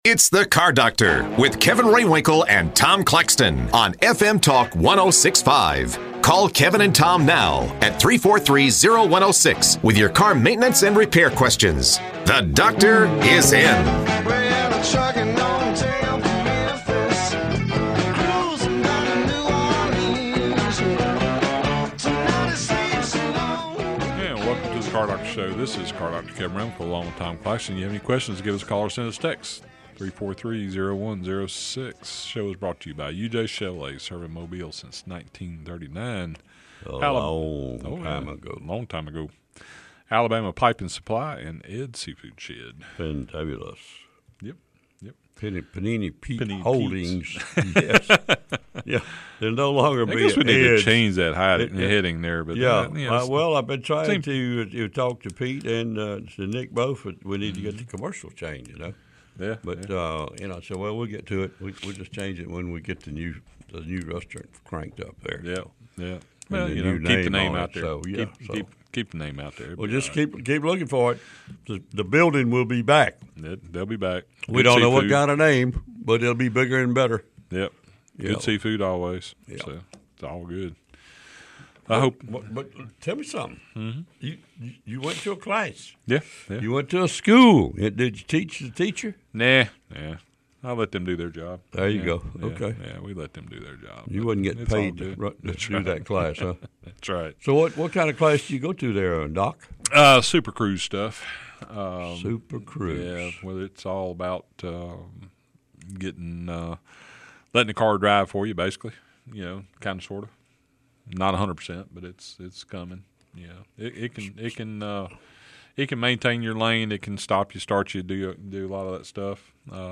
Automotive repair and racing experts